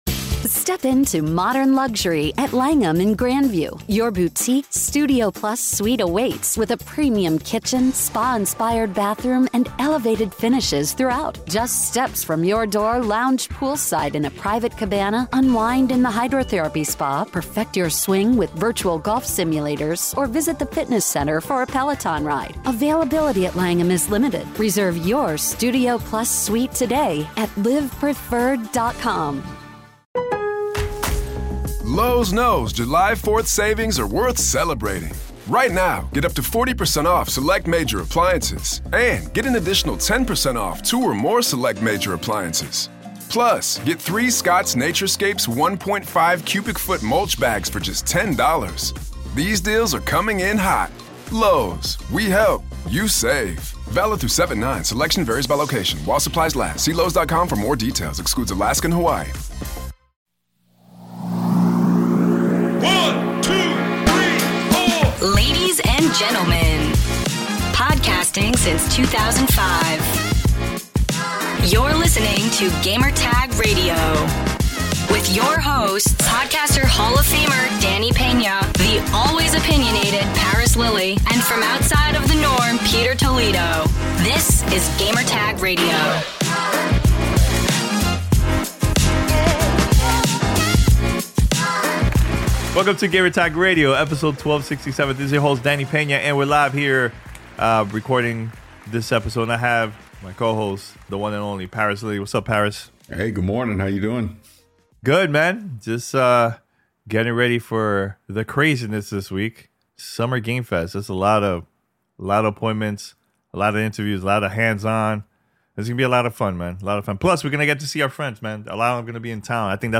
The Expanse: A Telltale Series Interview
This week on Gamertag Radio: The crew answer questions from the listeners about the PlayStation Showcase, GTR in 2006 and more. Also an interview with the dev team about The Expanse: A Telltale Series.